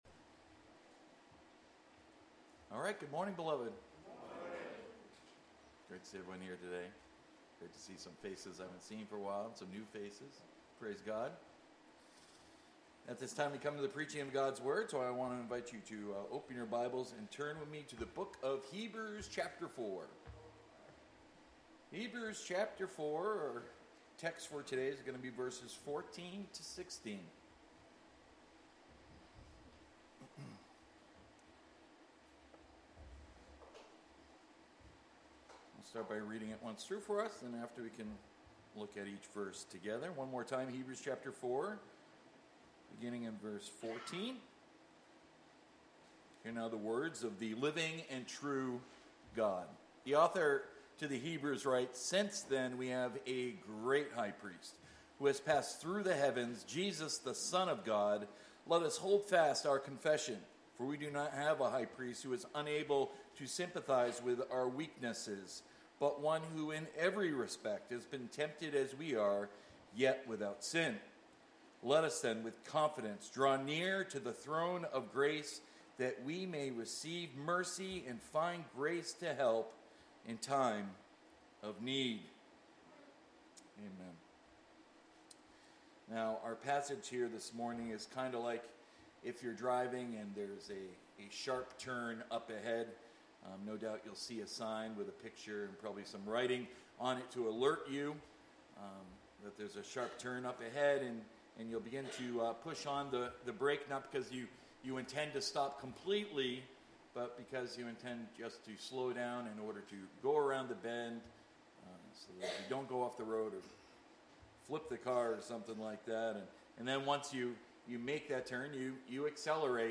Sermons by At the Cross